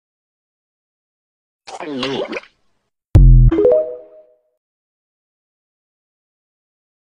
Glup Sound Effect Download: Instant Soundboard Button